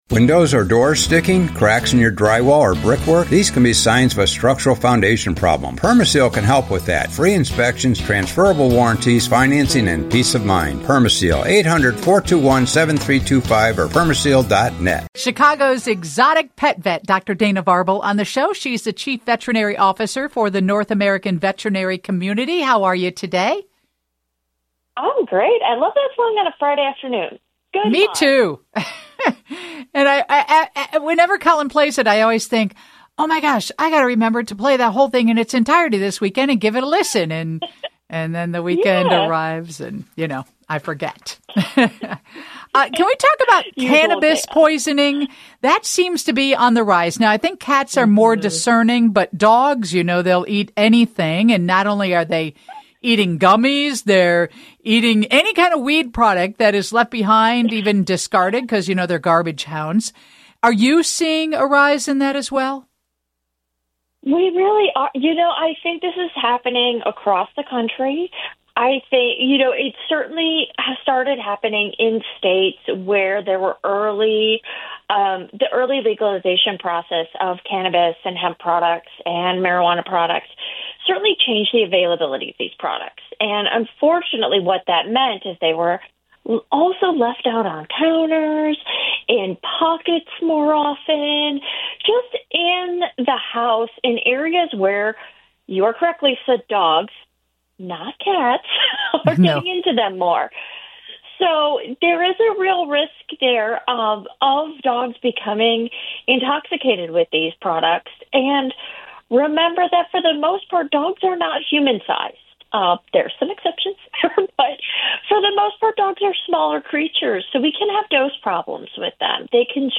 And, as always, she answers pet questions from listeners